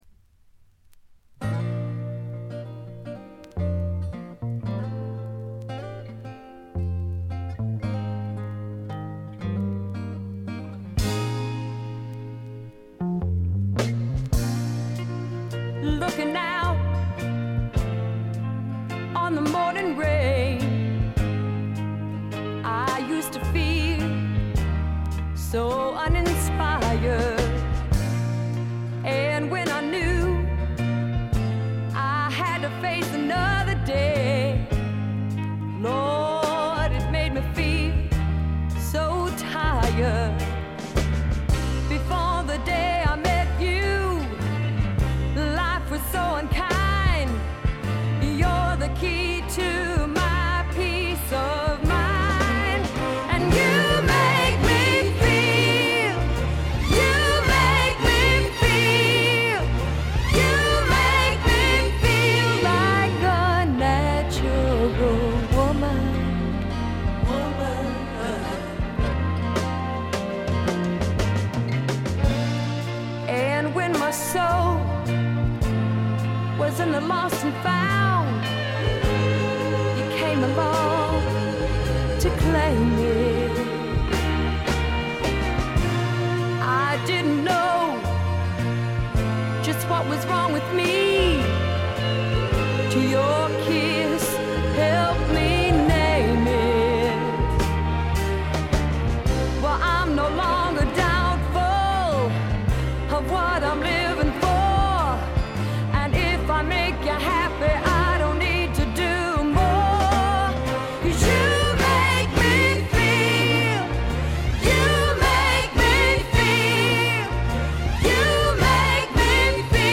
軽微なチリプチ少々。
ソウル系のカヴァーでは力強いシャウトで迫力のある歌声を聴かせてくれます。
スワンプ系女性ヴォーカル・アルバムの快作です。
試聴曲は現品からの取り込み音源です。